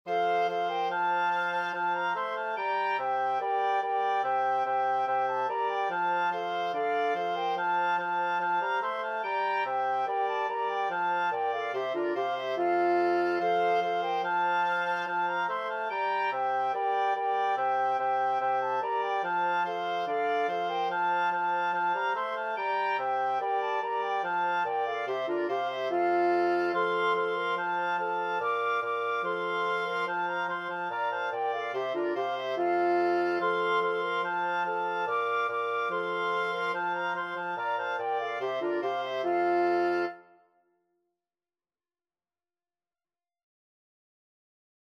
Free Sheet music for Wind Quartet
FluteOboeClarinetBassoon
4/4 (View more 4/4 Music)
F major (Sounding Pitch) (View more F major Music for Wind Quartet )
Classical (View more Classical Wind Quartet Music)